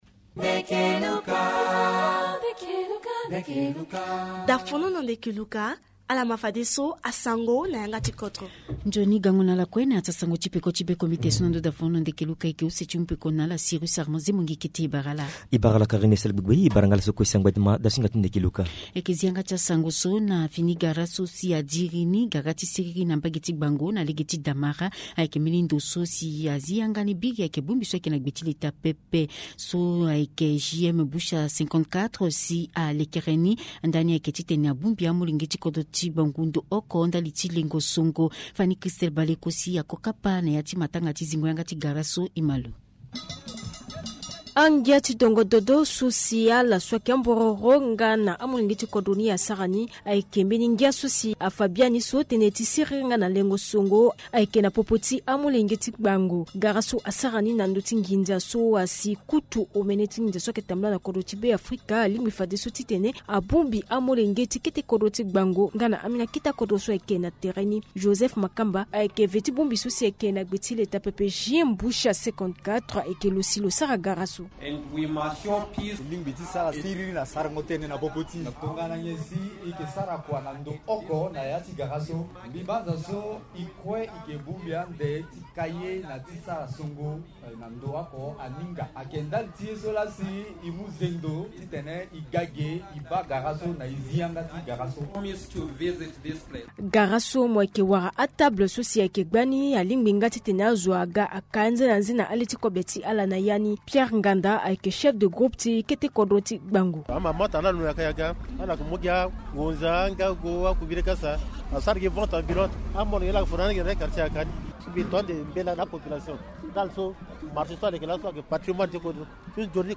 Journal Sango